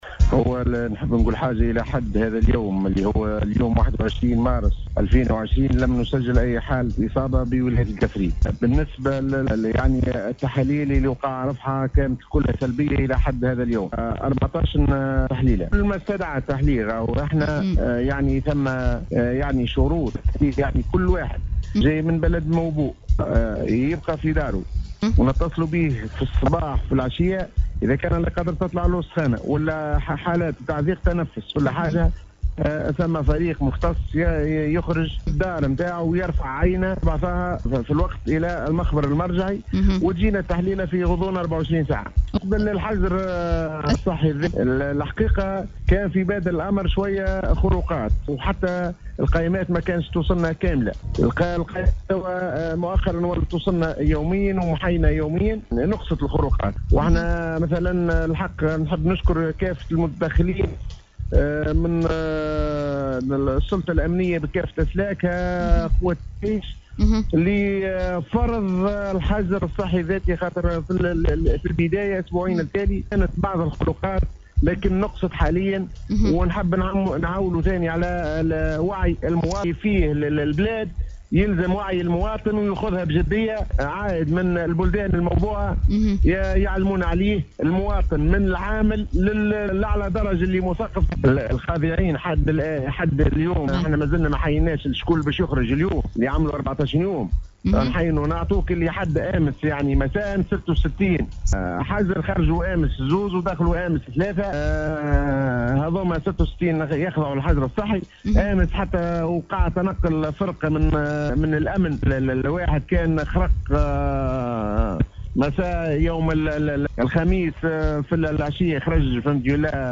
اكد المدير الجهوي للصحة بالقصرين الدكتور عبد الغني الشعباني في مداخلة هاتفية في برنامج “samedi show ” عبر موجة سيليوم اف ام ، على ضرورة التزام جميع القادمين الى ولاية القصرين من بلدان او ولايات اخرى بالحجر الصحي الذاتي لمدة 14 يوما ، وذلك لحماية انفسهم والمحيطين بهم من الاصابة بفيروس الكورونا المستجد في بلادنا .كما اشار الشعباني الى انه لم تسجل الى اليوم اي اصابة بهذا الفيروس في الجهة وقد تم رفع 14 تحليلا  ونتائجها جميعا كانت سلبية ، وان عدد الخاضعين للحجر الصحي بالجهة الى غاية يوم امس بلغ 66 شخصا ، مضيفا الى ان تم تسجيل نقصا ملحوظا في الخروقات للحجر الصحي وذلك من خلال تظافر جهود جميع الاطراف من مواطنين وصحة والجهات الامنية .